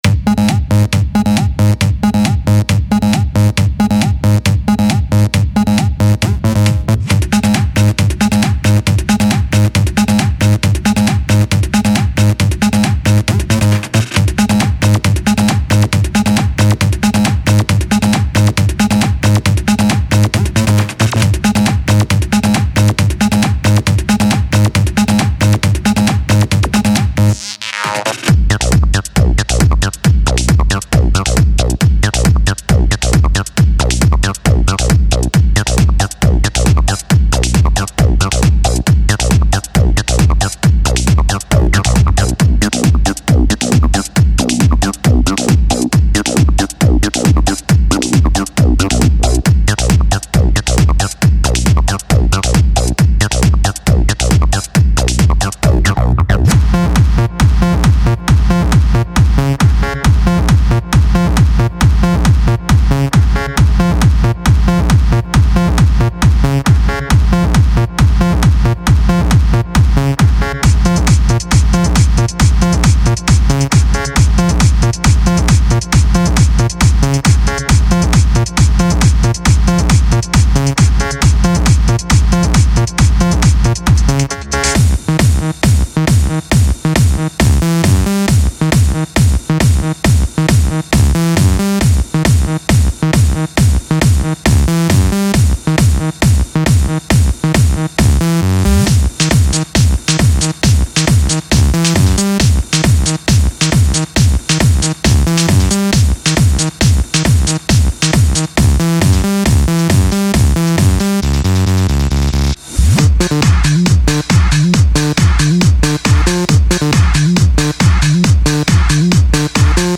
features 273 benassi style analog bassline phrases
with useful sidechain compression tweaks for variations and that
sought after pumping/ducking feel.
All loops are 100 royalty-free and recorded using analog gear.
35 construction kits including 100 bonus House beats with
separate; Kicks, HiHats,Percussions and Glitch Loop Combos